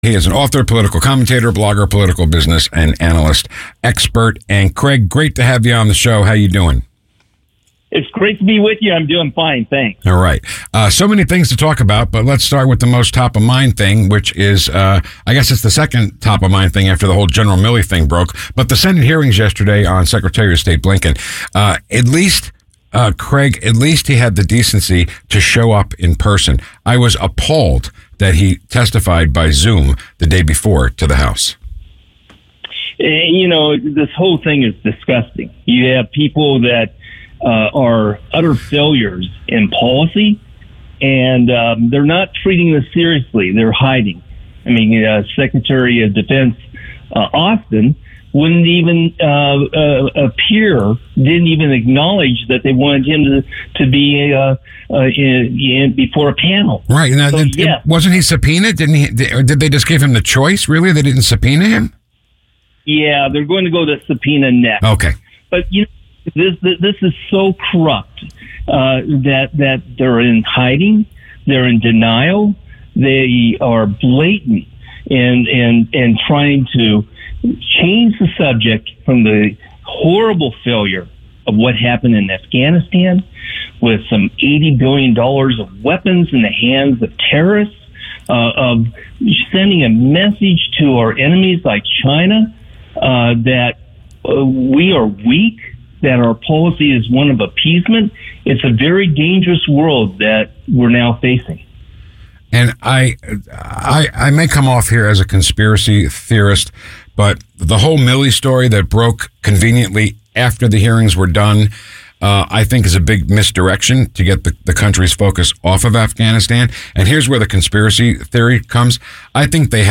[Must-See Interview]